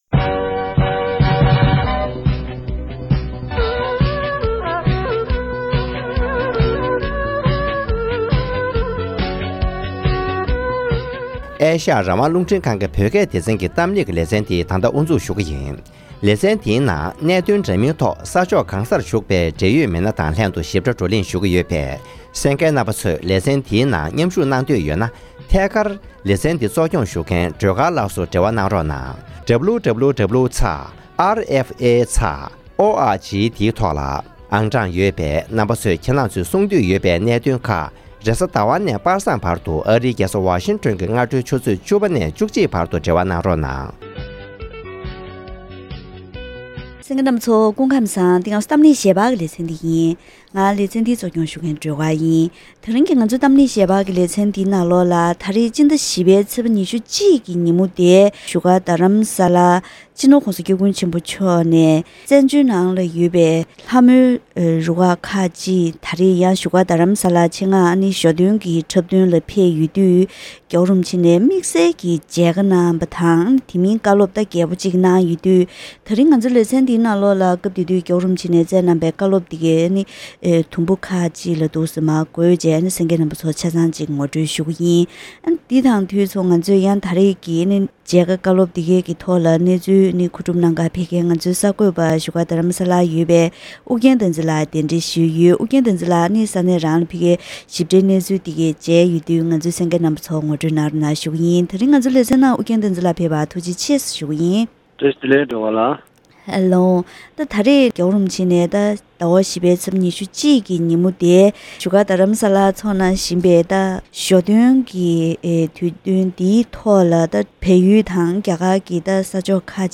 ༄༅༎དེ་རིང་གི་གཏམ་གླེང་ཞལ་པར་ལེ་ཚན་ནང་སྤྱི་ནོར་༧གོང་ས་༧སྐྱབས་མགོན་ཆེན་པོ་མཆོག་ནས་བཙན་བྱོལ་ནང་ཡོད་པའི་ལྷ་མོའི་རུ་ཁག་ཁག་ཅིག་གི་ཚོགས་མིར་མཇལ་ཁ་དང་བཀའ་སློབ་གནང་ཡོད་ཅིང་།